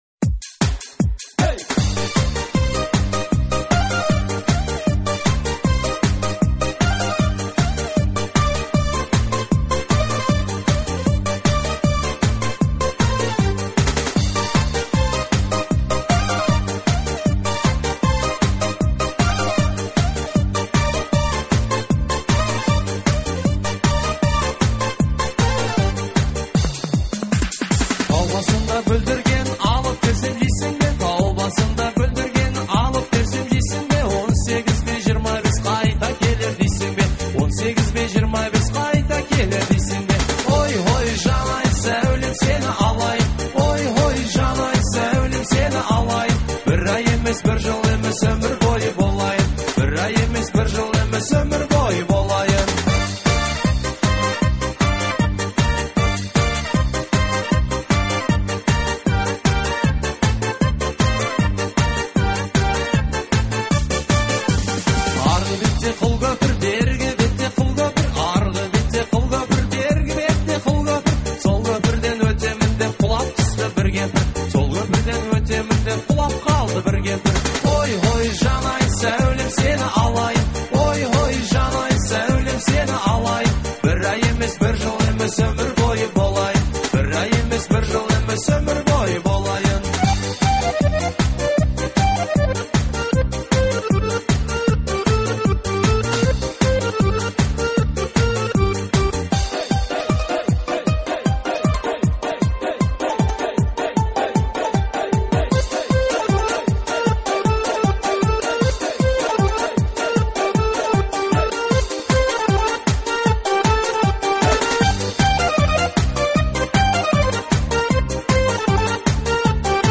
это зажигательная народная песня в жанре фольклор